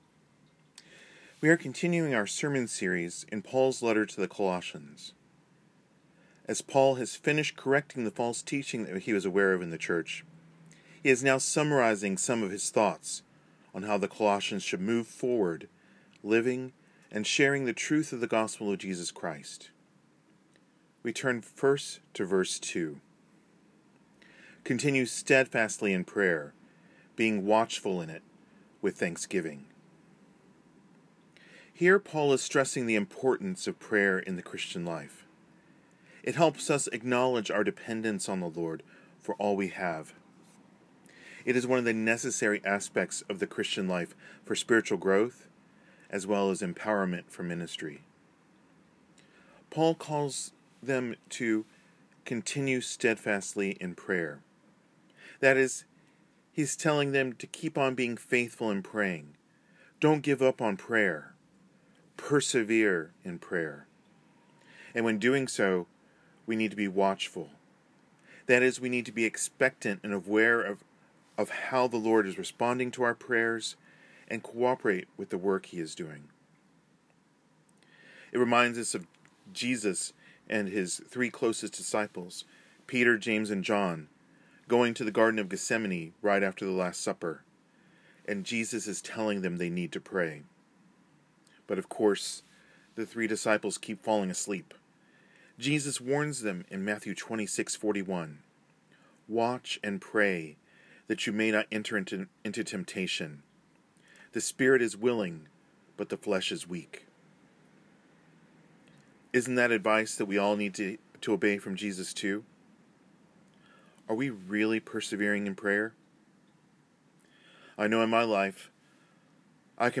Service Type: IBCP's Service